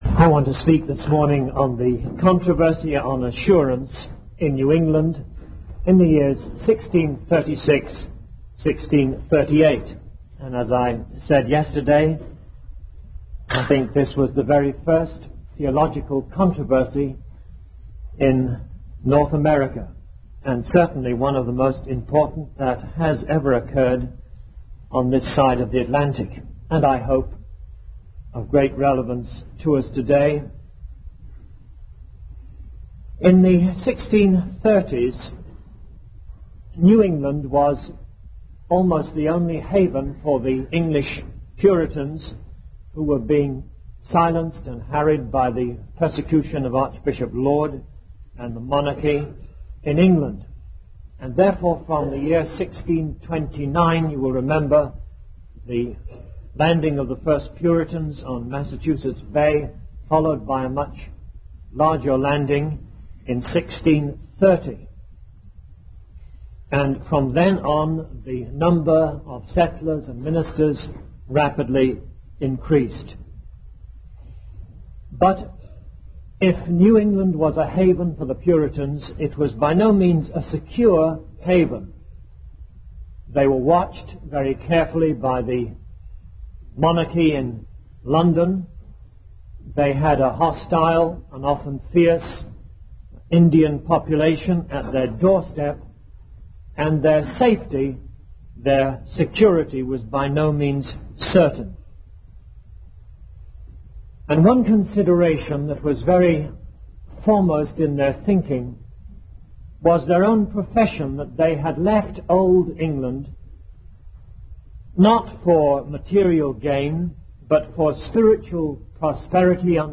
The sermon transcript discusses the controversy surrounding the assurance of salvation in the context of the New Covenant.